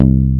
STINGER BS.wav